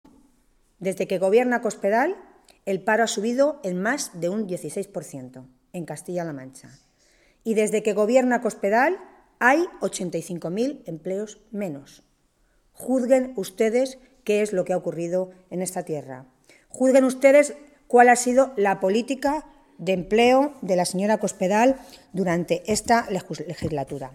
Tolón realizaba estas declaraciones en una rueda de prensa en las que valoró los datos del paro del mes de enero que se han conocido hoy.
Cortes de audio de la rueda de prensa